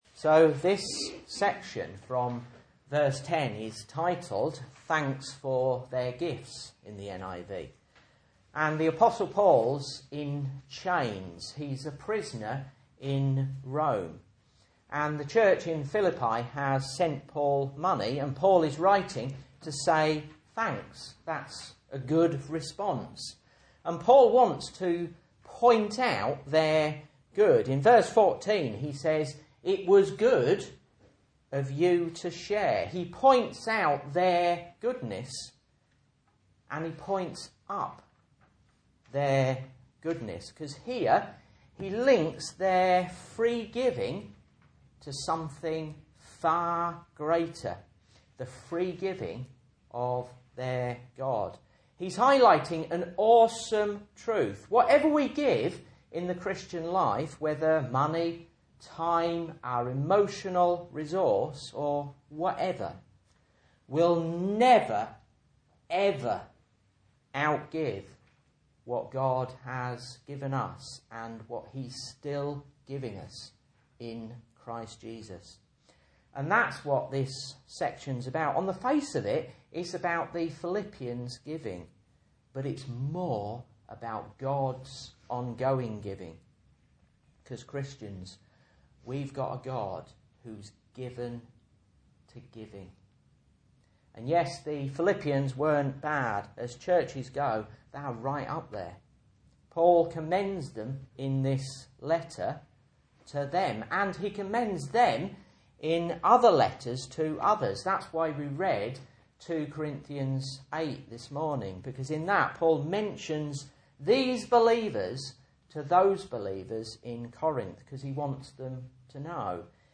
Message Scripture: Philippians 4:19-23 | Listen